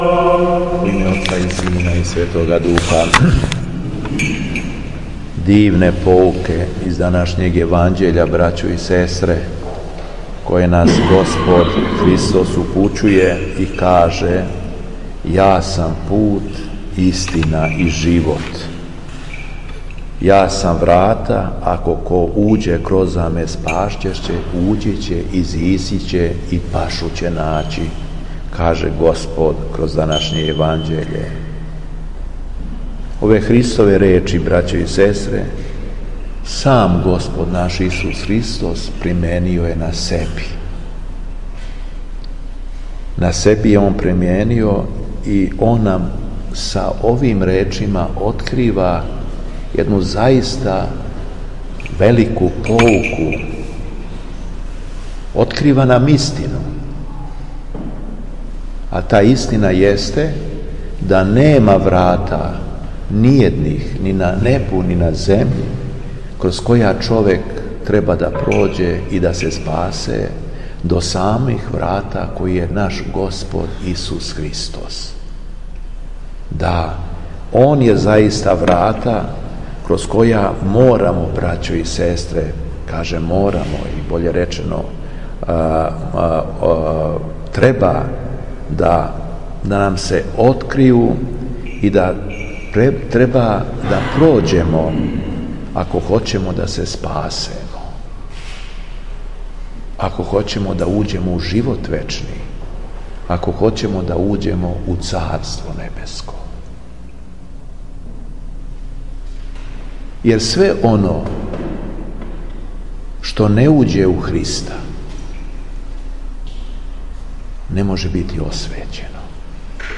Дан након Крстовдана, 28. септембра 2018. године, када наша Црква прославља светог великомученика Никиту и светог Јосифа Темишварског, Његово Преосвештенство Епископ шумадијски Господин Јован служио је свету Литургију у храму Свете Петке у Виноградима.
Беседа Епископа шумадијског Г. Јована